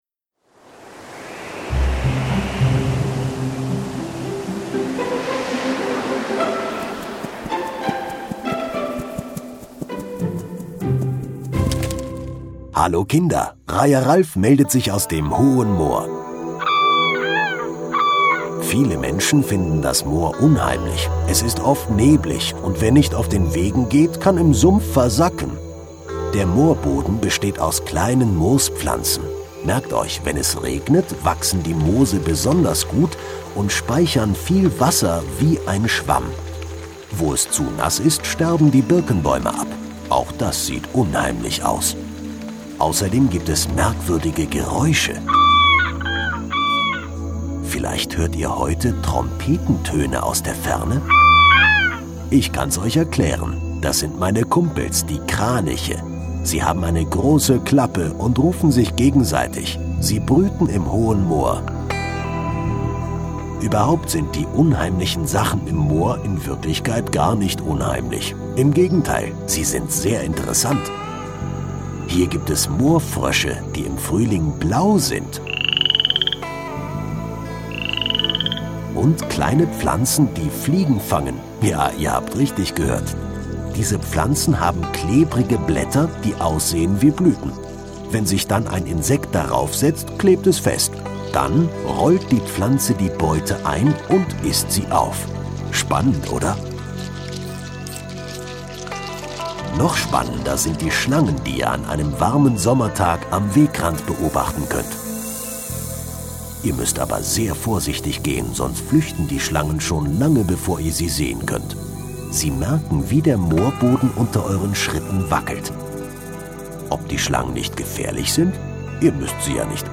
Hohes Moor - Kinder-Audio-Guide Oste-Natur-Navi